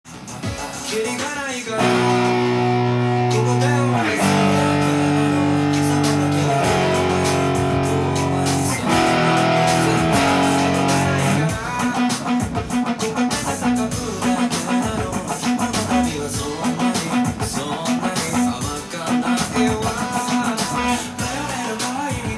ギターパート　オリジナルtab譜
音源にあわせて譜面通り弾いてみました
電子音が目立つカッコいいダンスナンバー
エレキギターを入れると更に良い感じになります。
シンコペーションというリズムが出てきます。
１６分音符のミュート音が入ってきています。